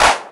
cch_clap_one_shot_mid_saturated_thous.wav